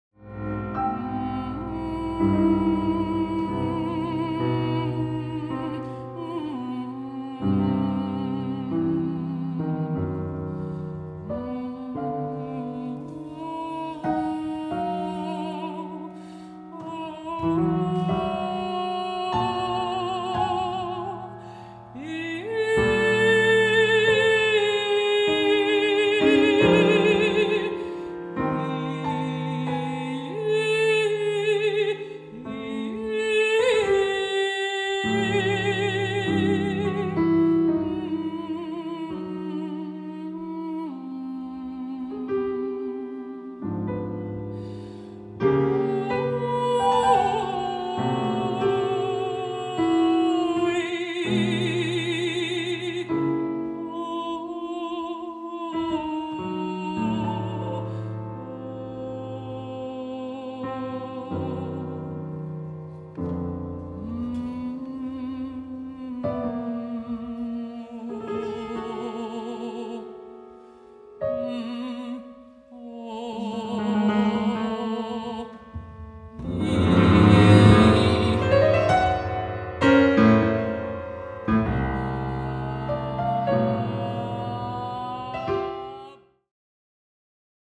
female voice & piano. 5 min. 40